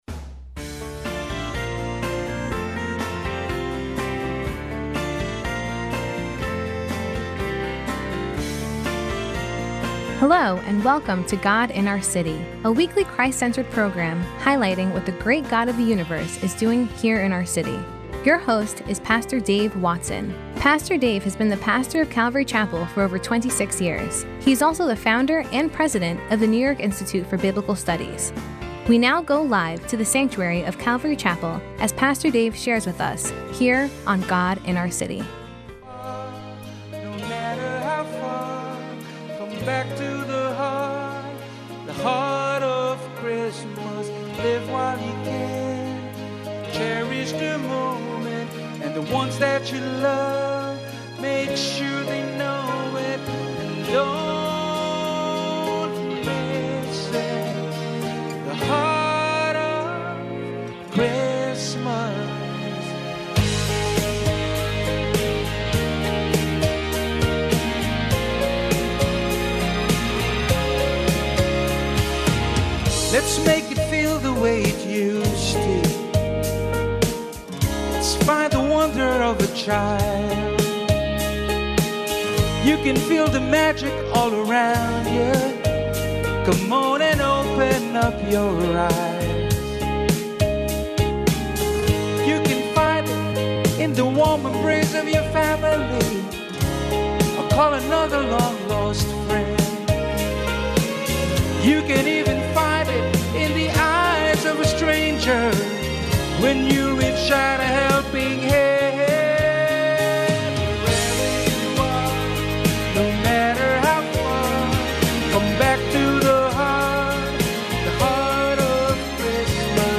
Podcast from yesterday’s service and sermon, God in Our City, Simply Christmas P. t1 – An Old Priest in the Temple, 12/4/2016